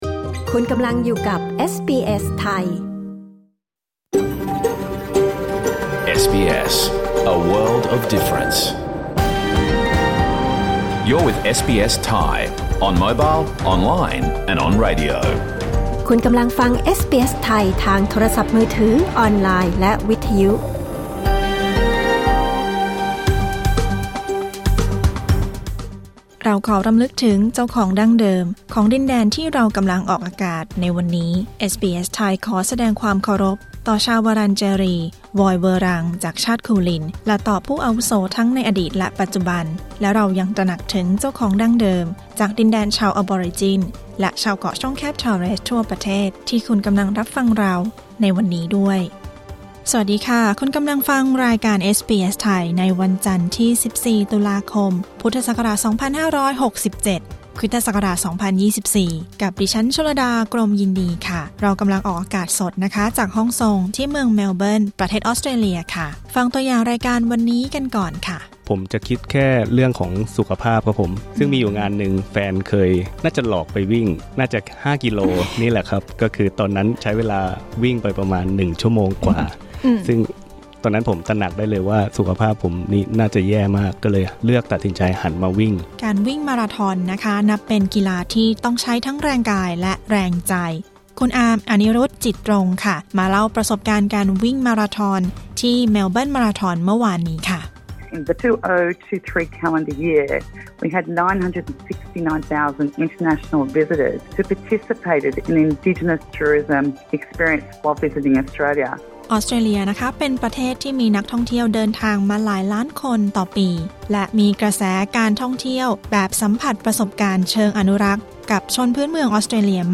Australia Explained: การท่องเที่ยวเชิงอนุรักษ์วัฒนธรรมพื้นเมืองออสเตรเลีย – บทสัมภาษณ์นักวิ่งมาราธอนคนไทย